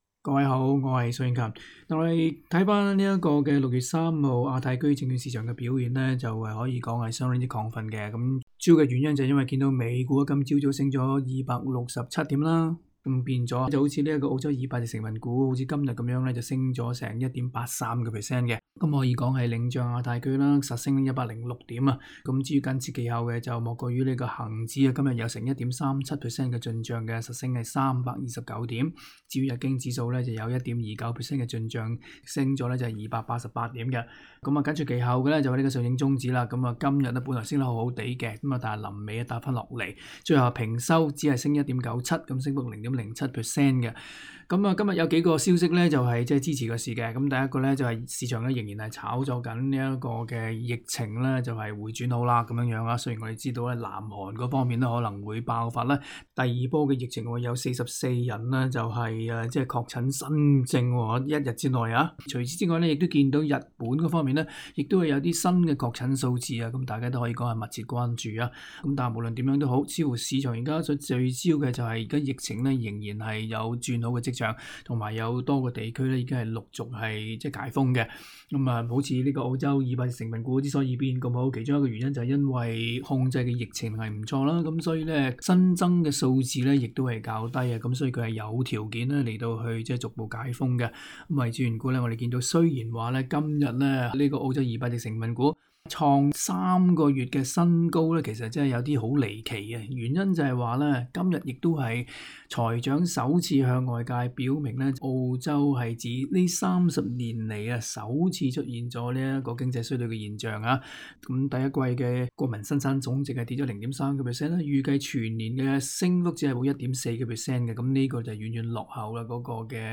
（详情请收听今日的访问） 为此缘故，市场有条件可以“主观“地炒作疫情有所改善，继续憧憬疫苗即将面世，甚麽美国种族动乱、中美贸易纠纷、香港国安法等政治风险，可以不用管， 起码暂时无需管。